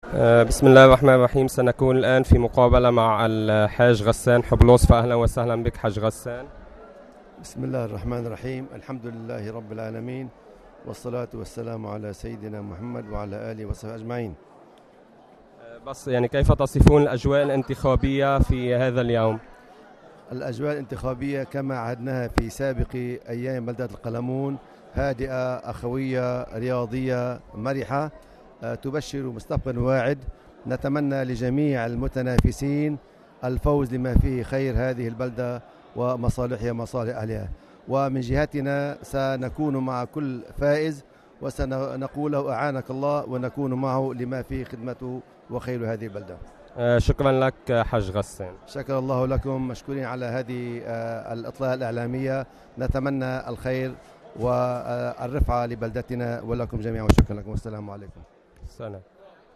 مقابلة